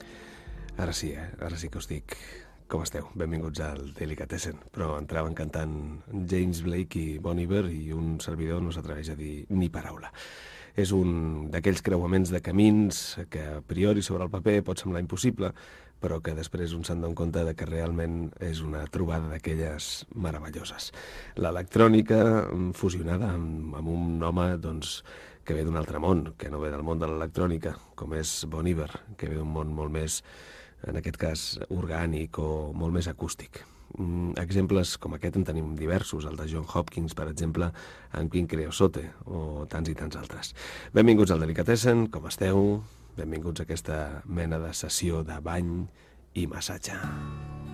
Inici i comiat del programa de música electrònica
Musical